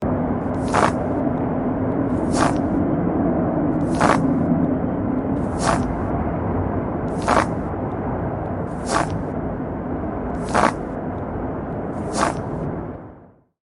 Walking In A Snowstorm
Walking In A Snowstorm is a free nature sound effect available for download in MP3 format.
Walking in a Snowstorm.mp3